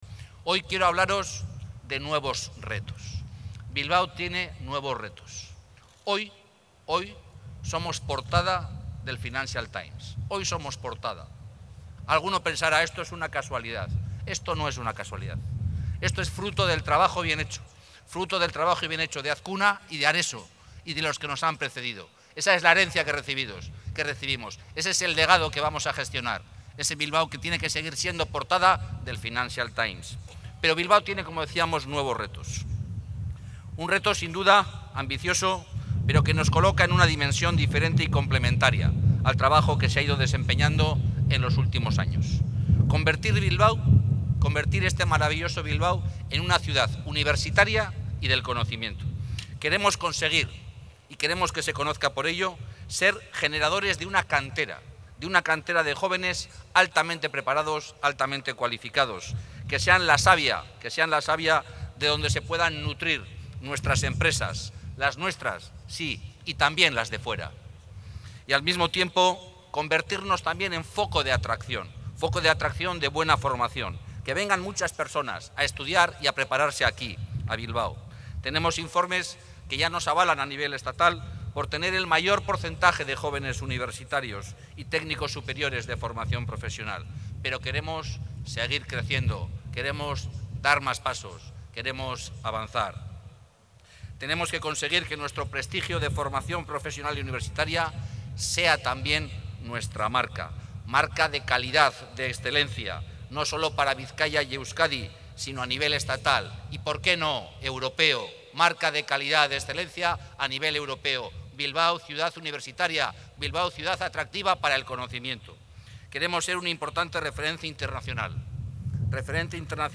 Hoy el candidato jeltzale se ha acercado hasta la zona de San Mames para hablar de un nuevo reto: convertir Bilbao en una auténtica ciudad universitaria y del conocimiento.”